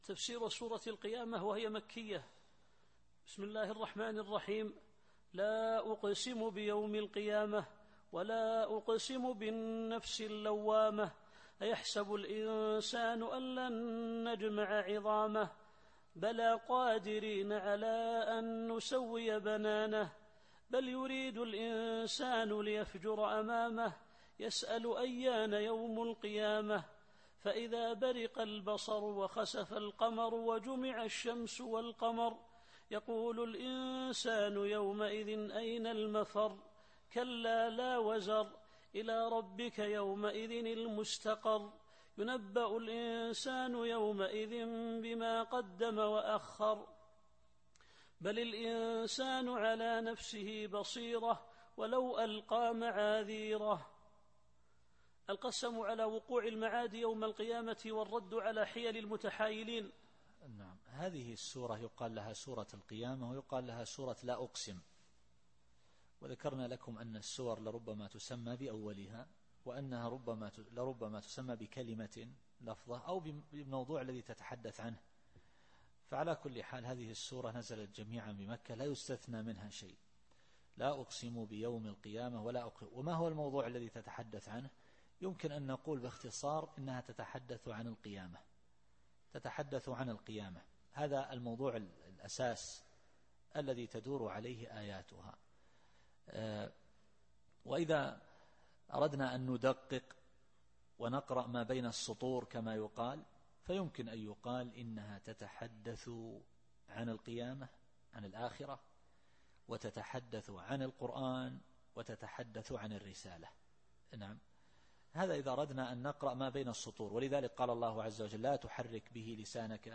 التفسير الصوتي [القيامة / 2]